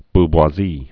(bbwä-zē)